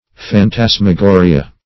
Phantasmagoria \Phan*tas`ma*go"ri*a\, n. [NL., from Gr.